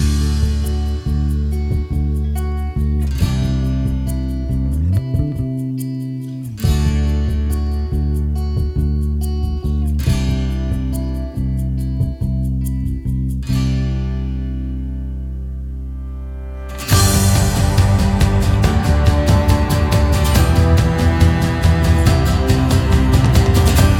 no Backing Vocals Pop (2010s) 3:52 Buy £1.50